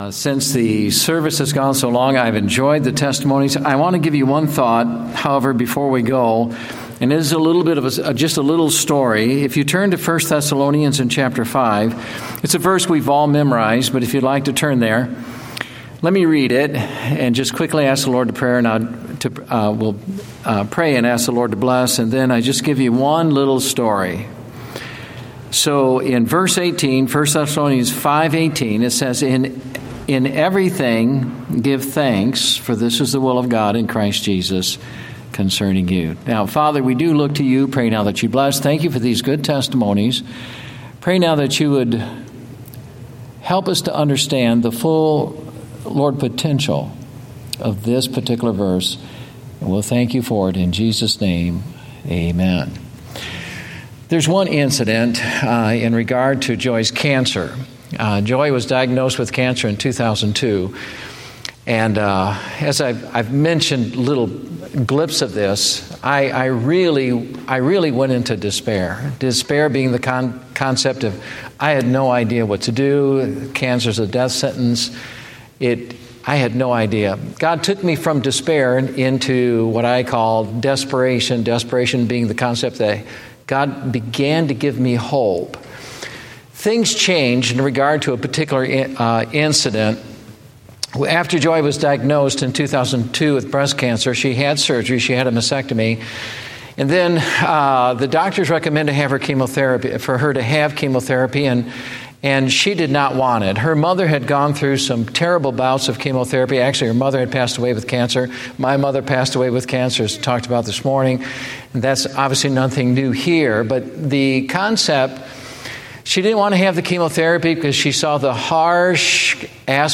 Date: August 24, 2014 (Evening Service)